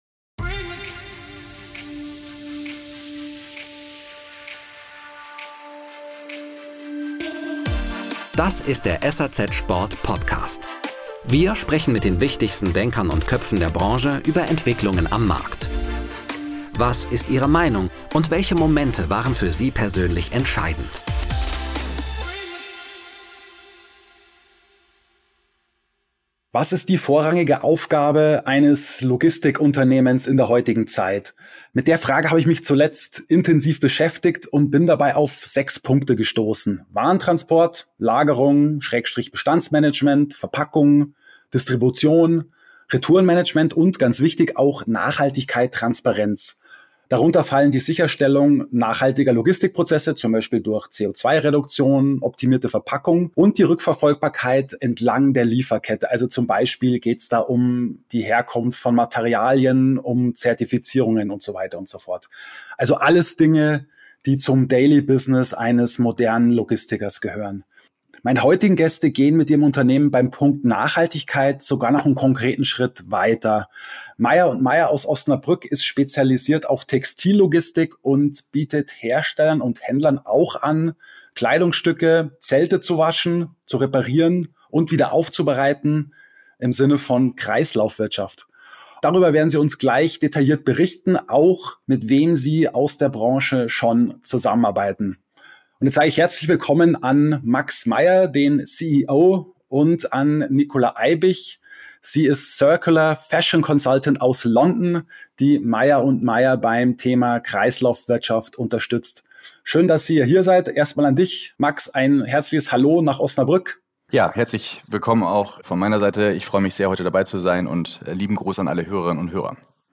Ein Podcast-Gespräch über zirkuläre Logistik und Branchenkooperationen.